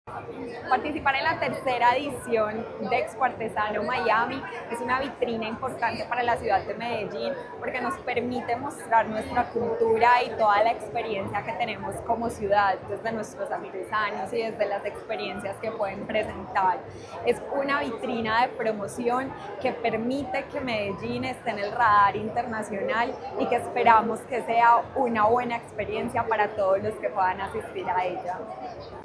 Declaraciones secretaria (e) de Turismo y Entretenimiento, Ana María Mejía
Declaraciones-secretaria-e-de-Turismo-y-Entretenimiento-Ana-Maria-Mejia.mp3